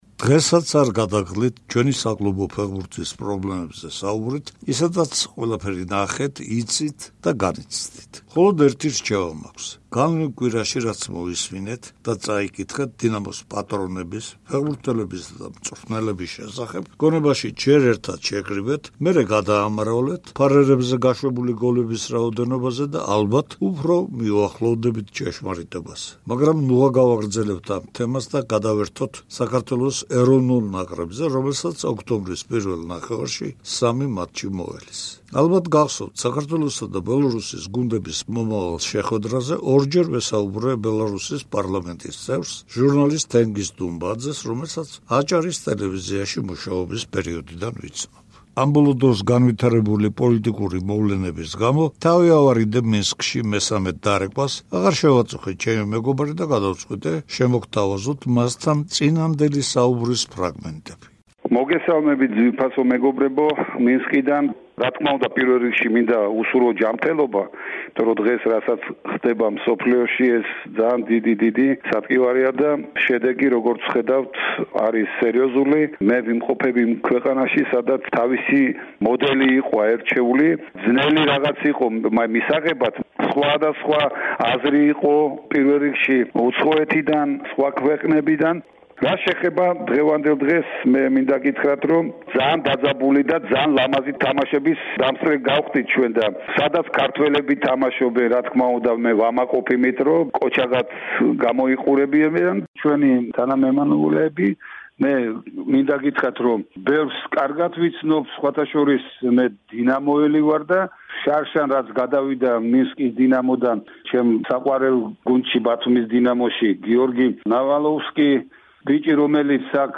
ჩვენს ქვეყანაში ფეხბურთის შემოსვლისა და დამკვიდრების ისტორიას შეახსენებს რადიომსმენელებს მხატვრული ფილმის „პირველი მერცხლის“ ფრაგმენტები. შეტევაზეა ფეხბურთი...